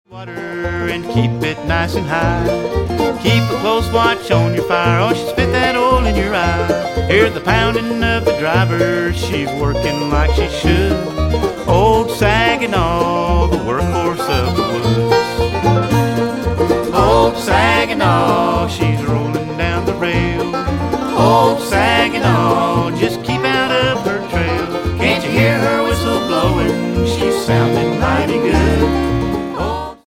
guitar
mandolin
fiddle
banjo
string bass